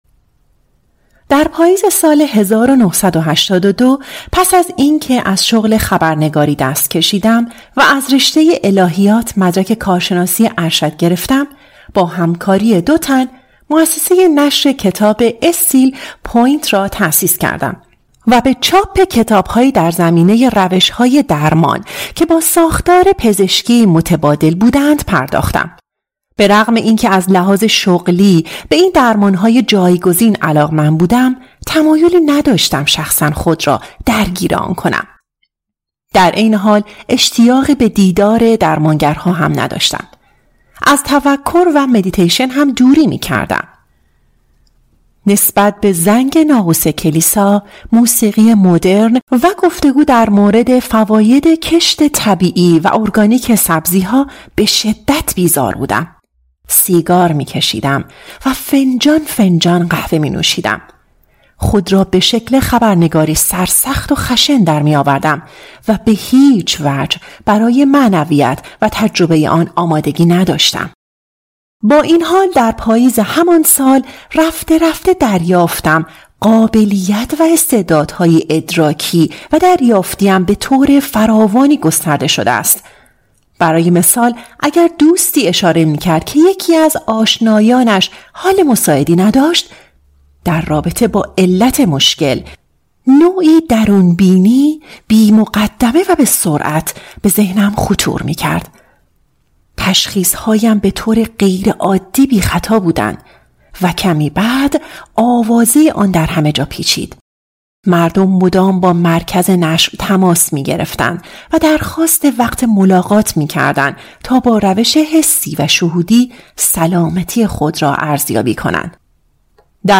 گزیده ای از کتاب صوتی